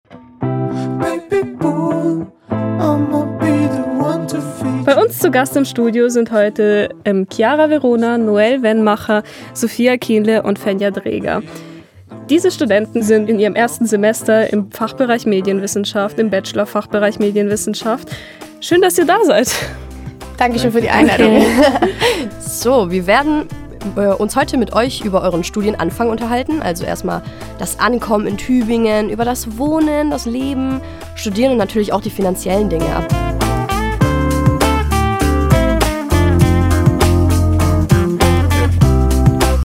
Studierende aus dem ersten Semester im Fach Medienwissenschaft an der Uni Tübingen berichten von ihren Erfahrungen bzgl. wohnen, leben und studieren.
Alle saßen das erste Mal in einem Hörfunkstudio.